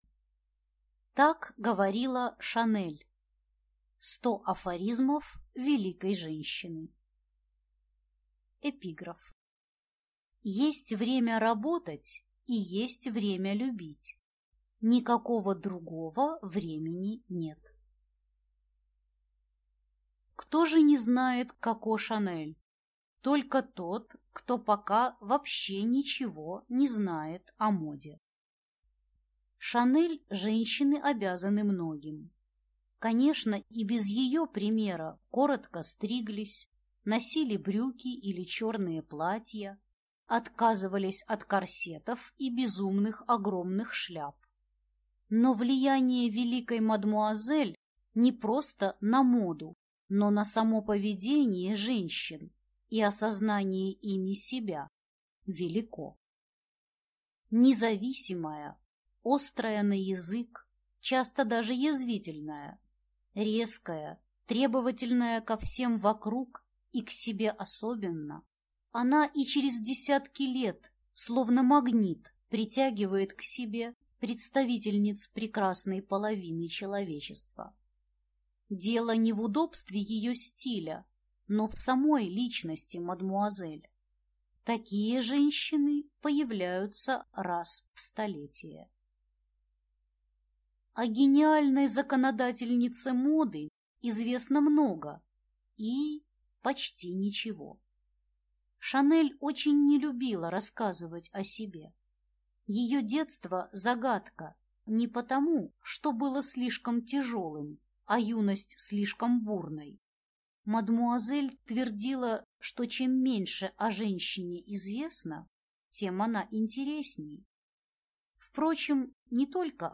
Аудиокнига Так говорила Шанель. 100 афоризмов великой женщины | Библиотека аудиокниг